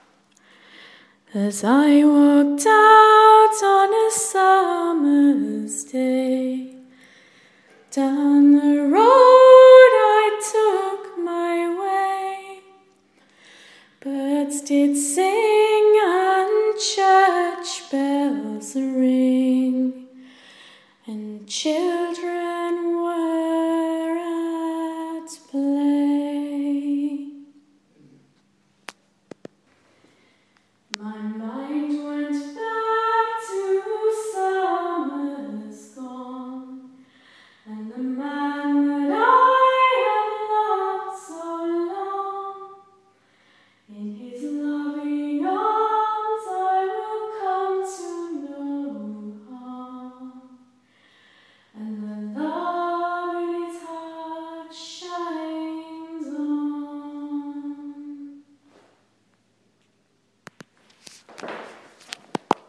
Looboo. I had to record myself singing in that acoustic!